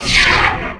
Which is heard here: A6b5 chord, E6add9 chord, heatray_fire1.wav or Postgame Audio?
heatray_fire1.wav